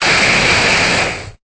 Cri de Kyurem dans Pokémon Épée et Bouclier.